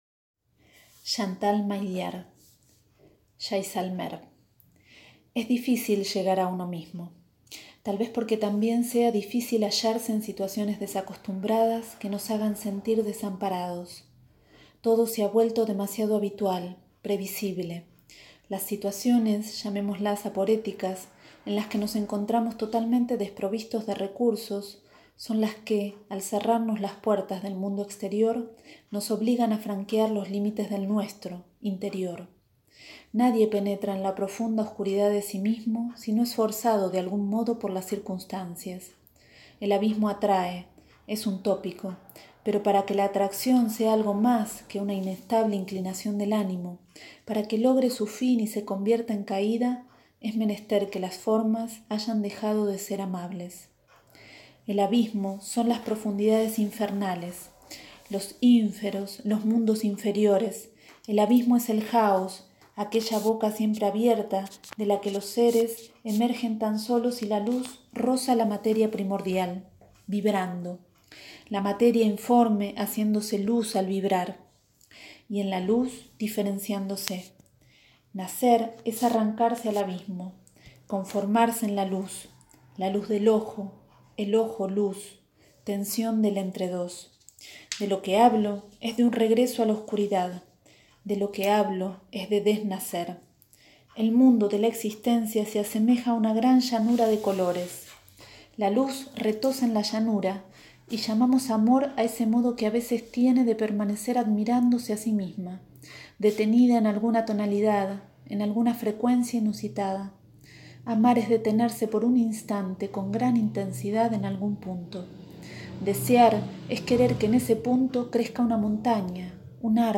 Escucho  una y otra vez este audio, como un canto sereno y hondo que me lleva al silencio.
A escuchar, como un canto.